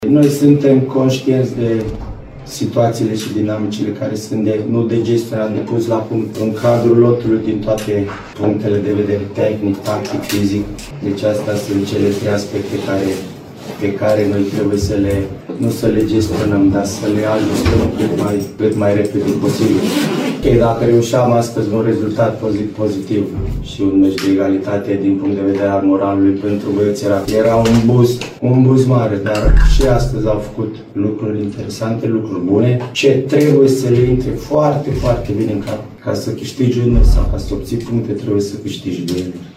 De cealaltă parte, hunedoreanul Bogdan Lobonț, care a preluat conducerea tehnică a echipei din Satu Mare, spera la un punct de moral pentru elevii săi: